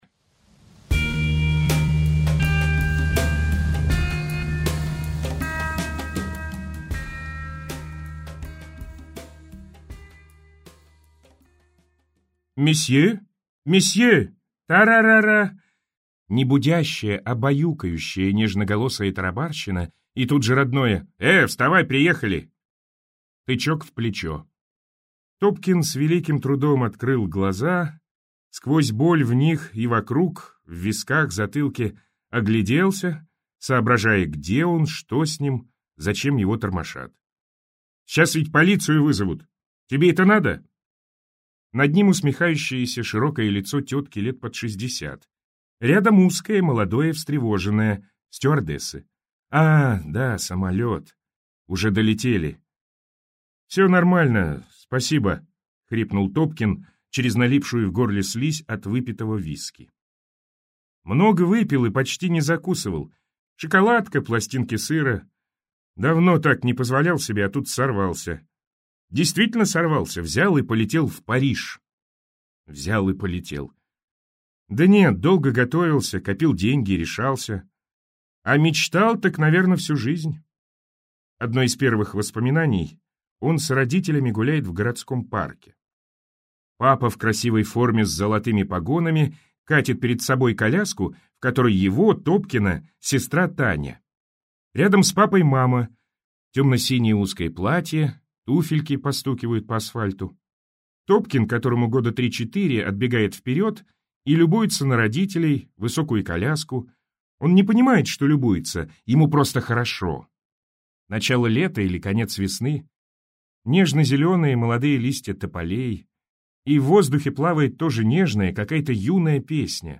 Аудиокнига Дождь в Париже - купить, скачать и слушать онлайн | КнигоПоиск